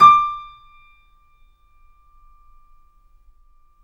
Index of /90_sSampleCDs/Roland L-CD701/KEY_Steinway ff/KEY_Steinway M